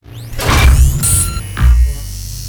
gauss_charge_start.wav